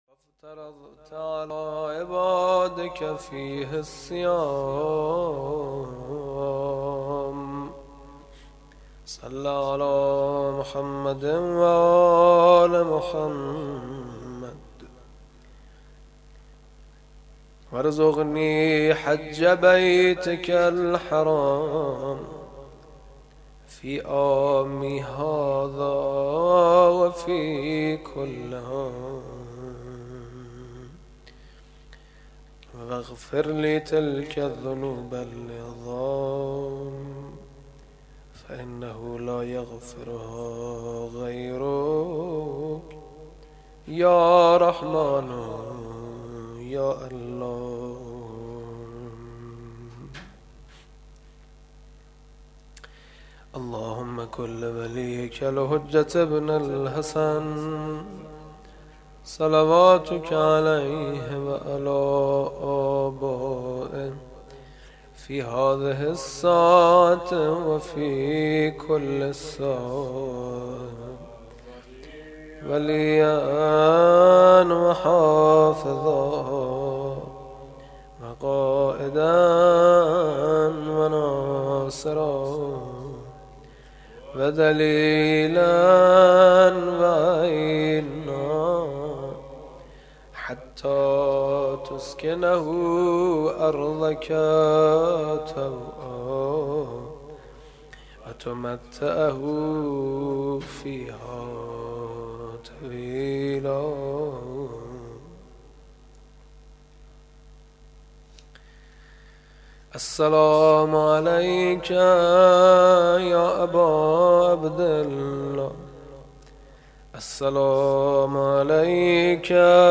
دریافتعنوان: ۵ رمضان ۱۴۳۰؛ ۴ شهریور ۱۳۸۸حجم: 13.6 مگابایتتوضیحات: زیارت عاشورا